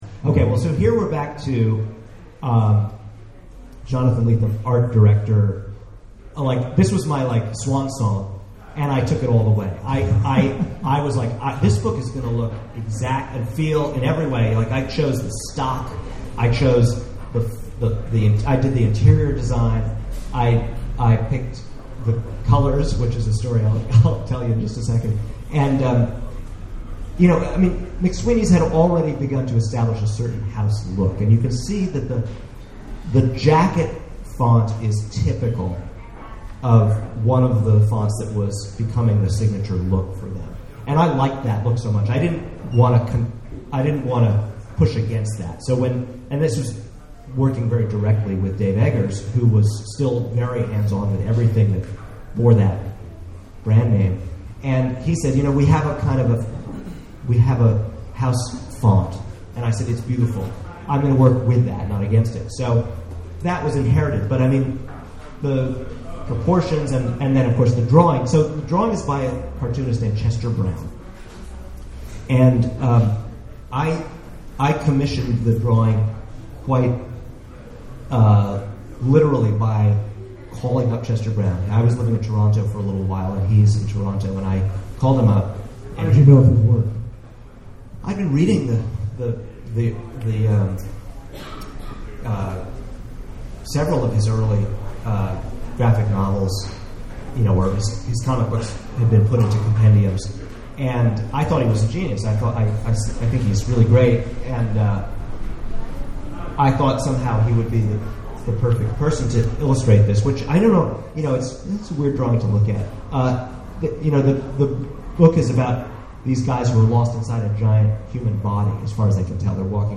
at The Last Bookstore in downtown Los Angeles for an in-depth discussion of his book covers.